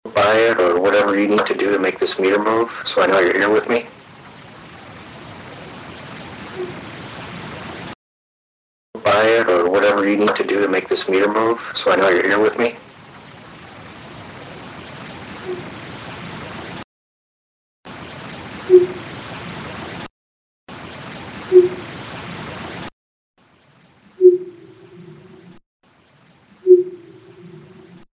Hmmph - In the cemetery room, or now I guess, the records room, I was asking whatever was there to attempt to make my EVP meter move. You hear what sounds like a child say hmmph! Original X2, amplified X2, cleaned and amplified X2.